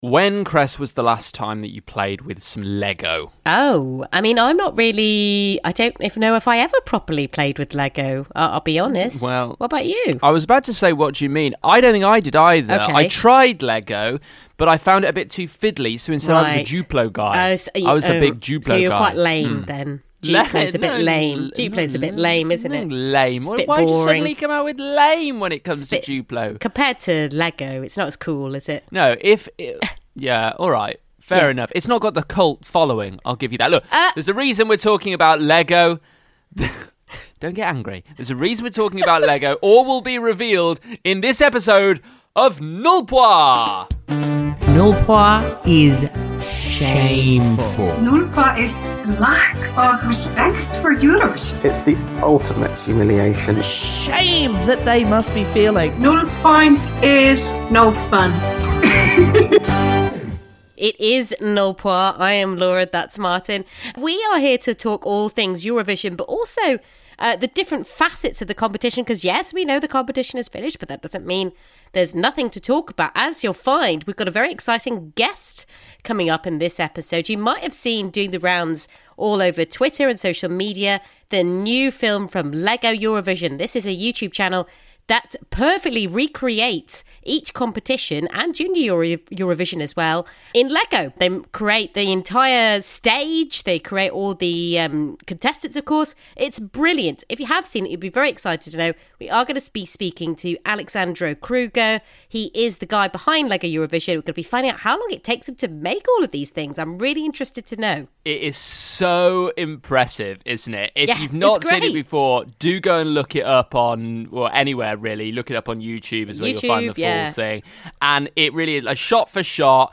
UK Eurovision fans